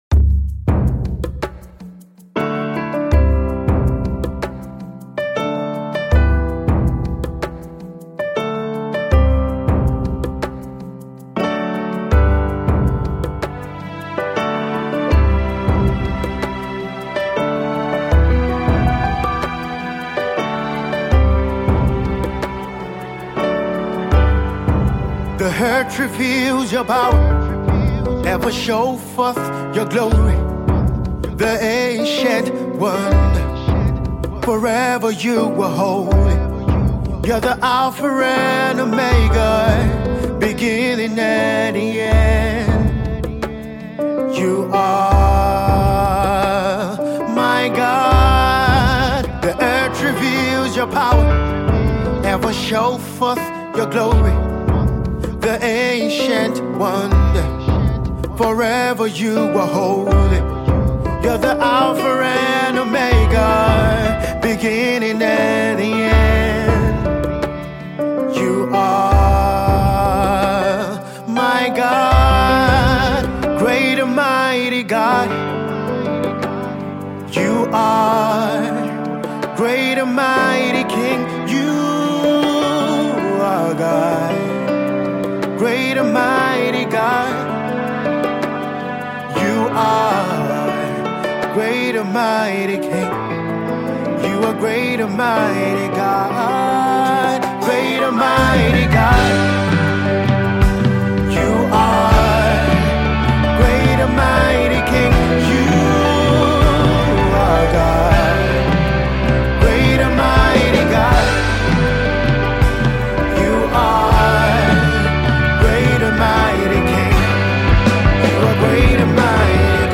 sonorous singer and excellent vocalist